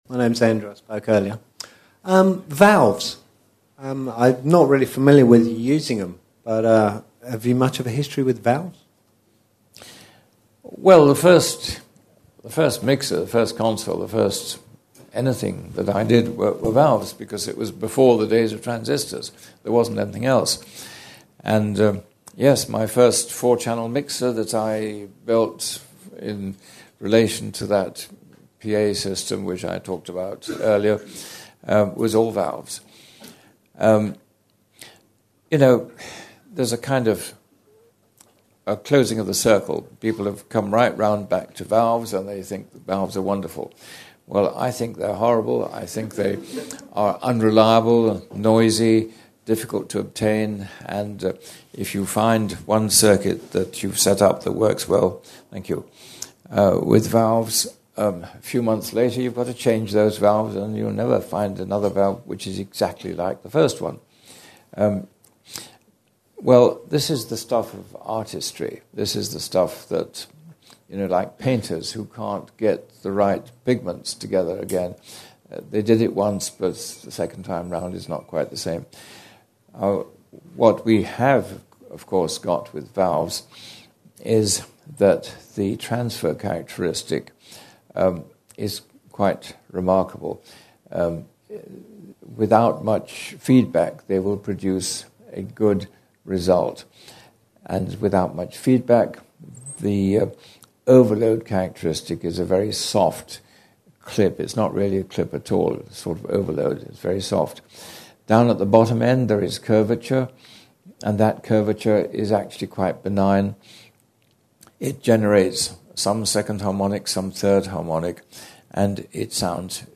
upstairs at The Bath House,
As before we recorded the evening'a procedings for those members who were not able to attend in person, and for non-members
Mono mp3 files at VBR